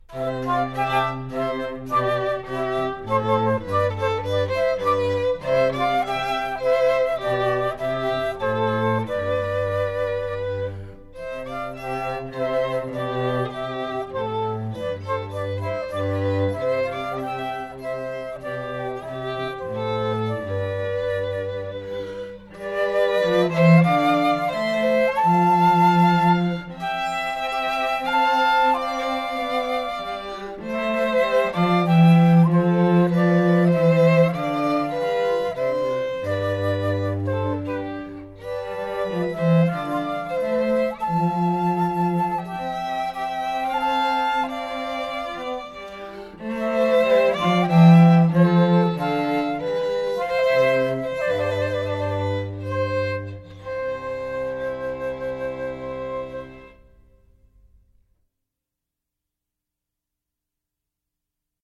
The Times Trio is an elegant, classical trio of flute, violin and cello.